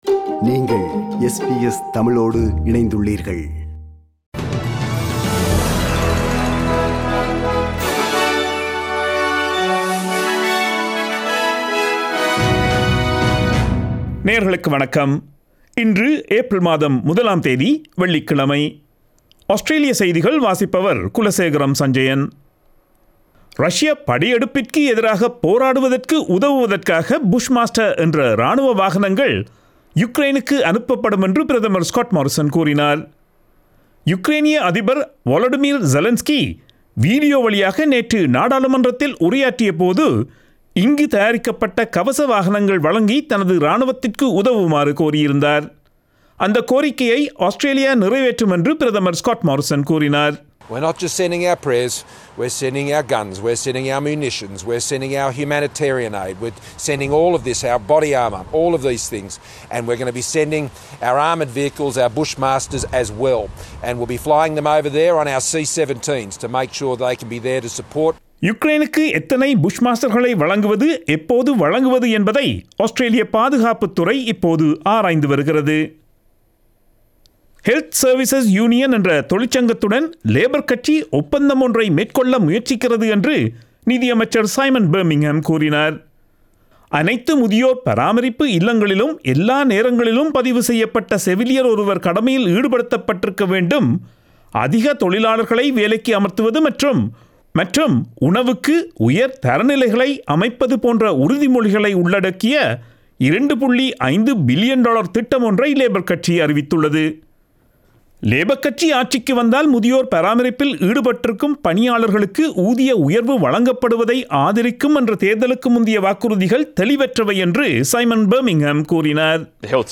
Australian news bulletin for Friday 01 April 2022.